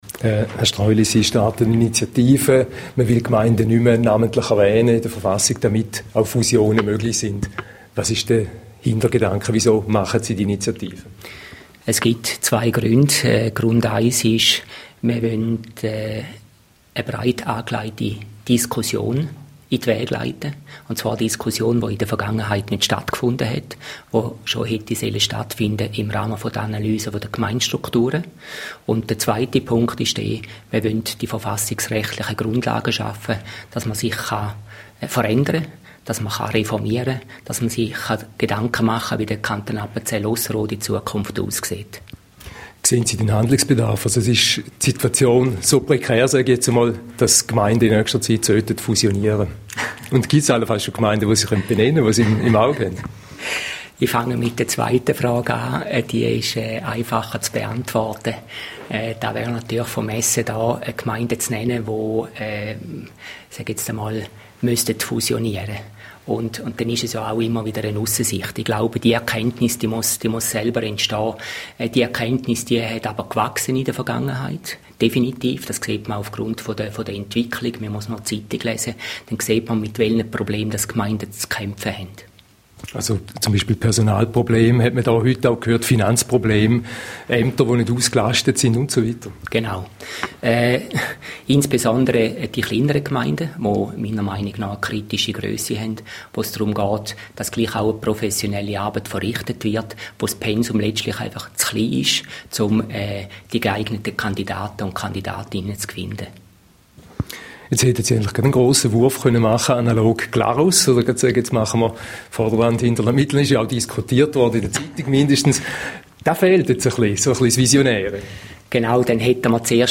Interview-mit-Regionaljournal-Ostschweihz.mp3